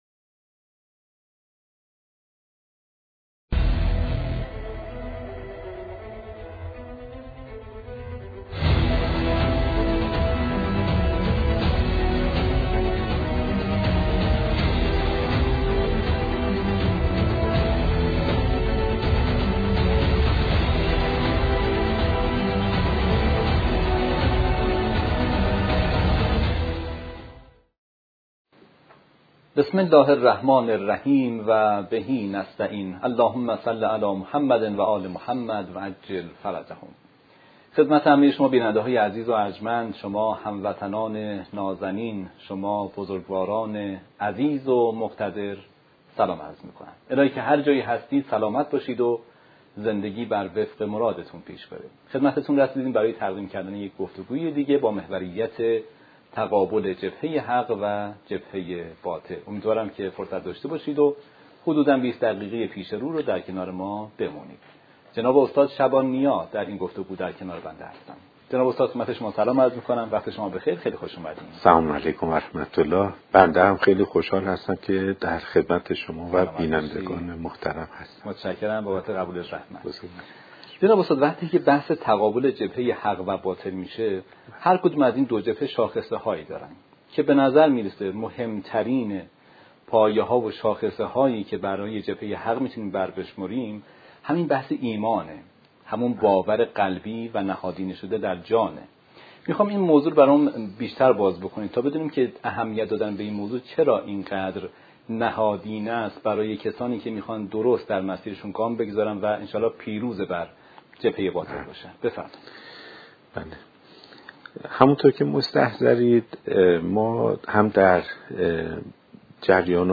این گفتگوی عمیق و معنوی درباره تقابل جبهه حق و باطل و نقش ایمان در پیروزی حق، نکات ارزشمندی را مطرح کرده است.